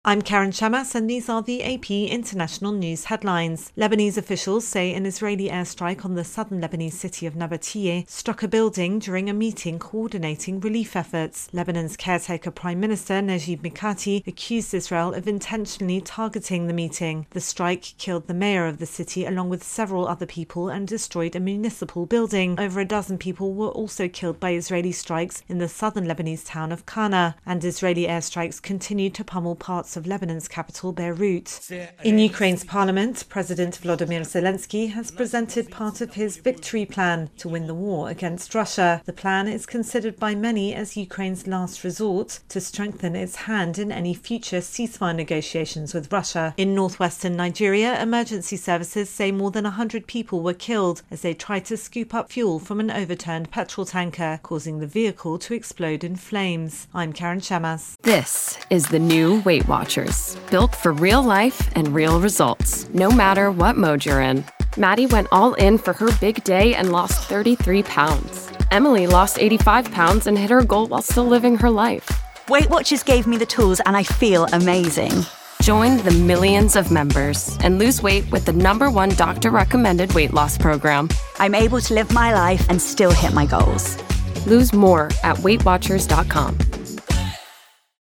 Latest Stories from The Associated Press / The latest international headlines